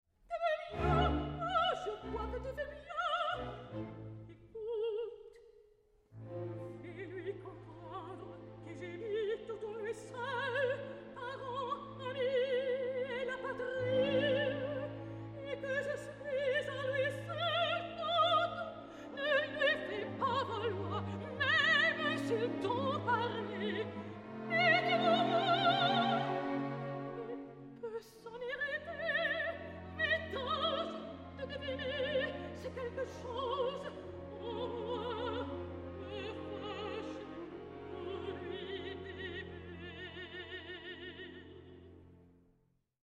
Sacred and Profane Arias